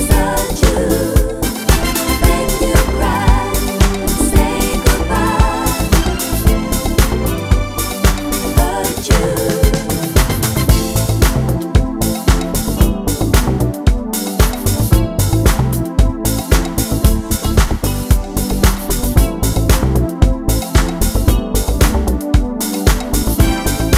One Semitone Down Pop (1980s) 3:37 Buy £1.50